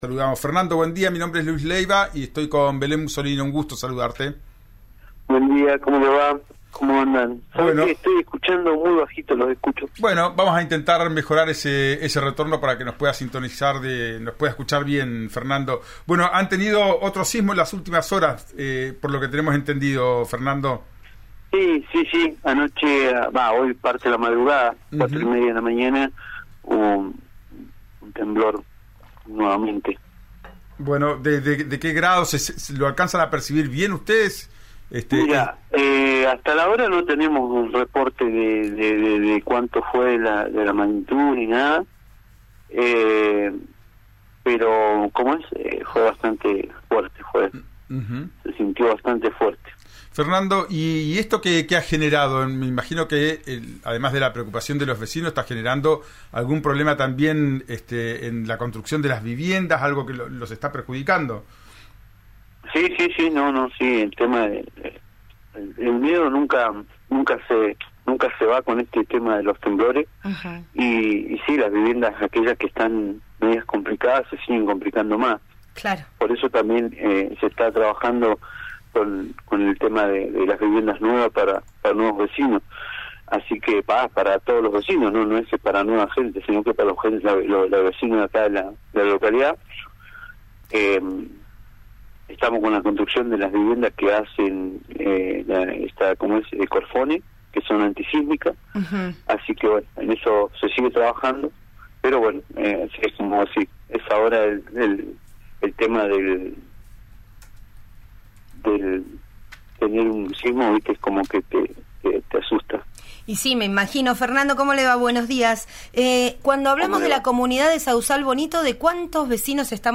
Escuchá a Fernando Wircaleo, presidente de la Comisión de Fomento del Sauzal Bonito, en «Ya es tiempo» por RÍO NEGRO RADIO: